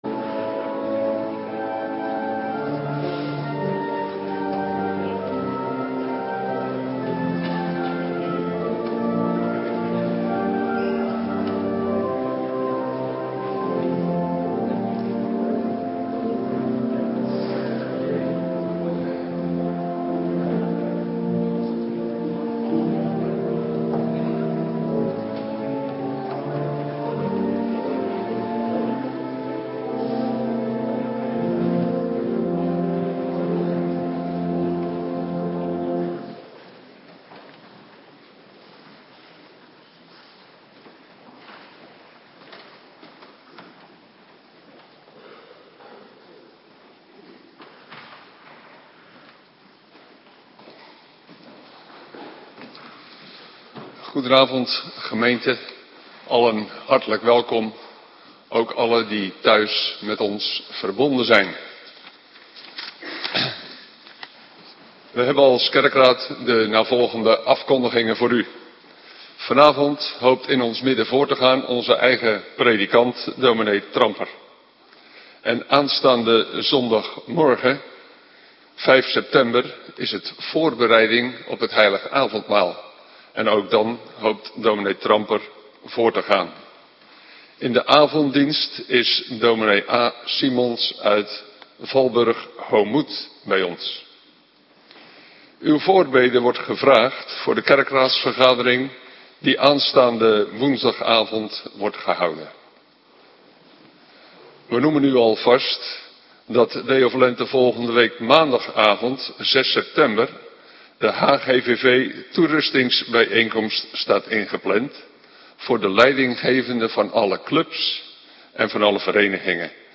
Avonddienst - Cluster B